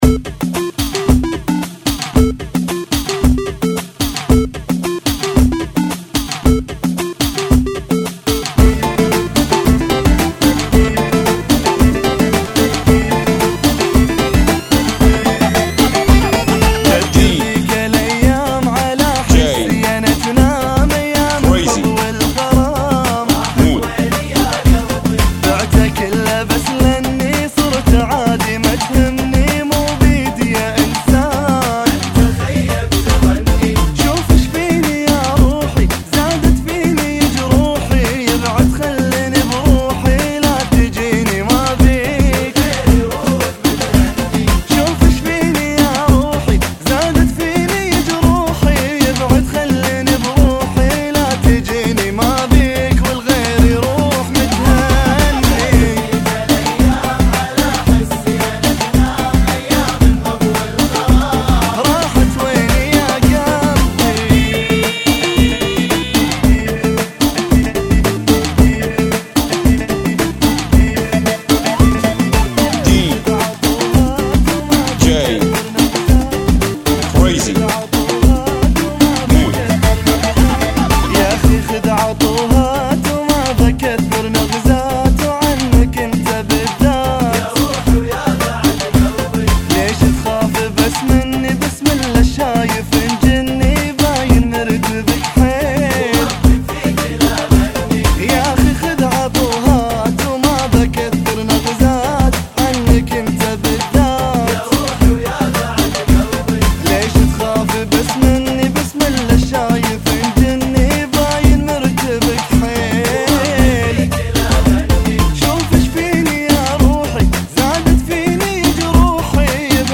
Funky Mix